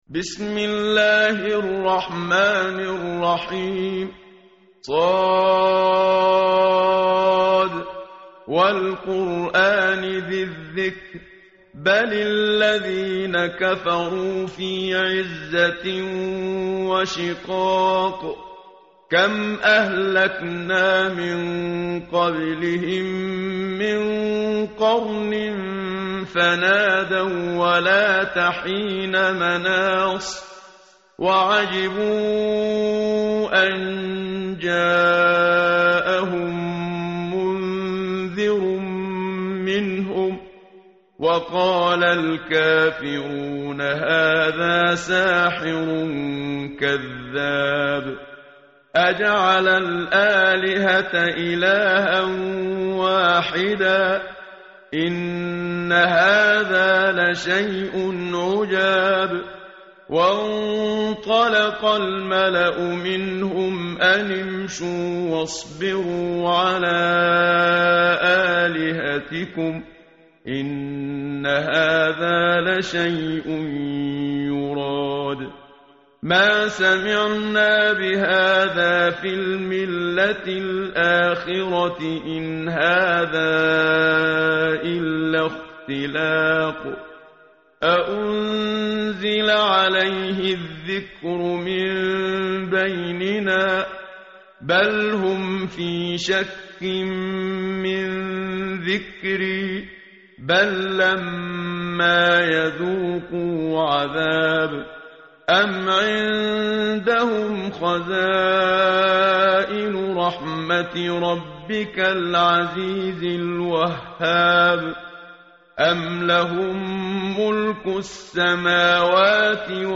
tartil_menshavi_page_453.mp3